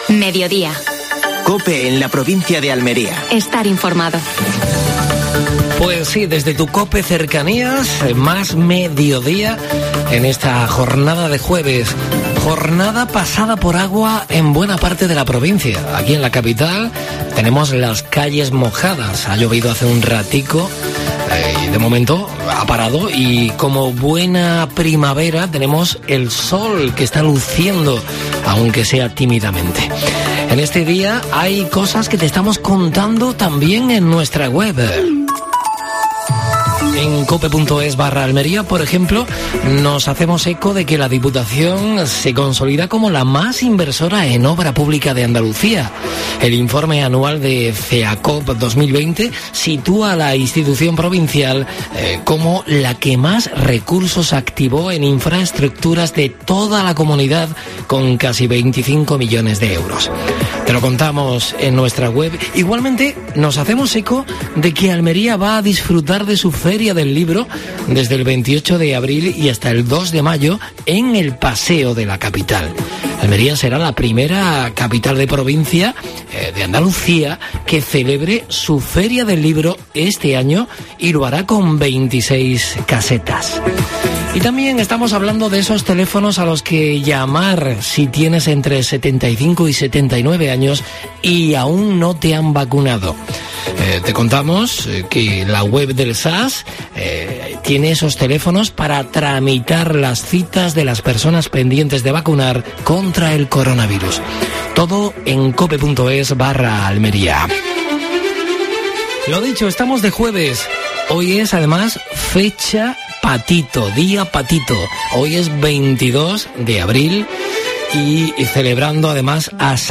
Entrevista a Luis Columna (presidente Audiencia Provincial). ¿Qué pasó tal día como hoy en la provincia?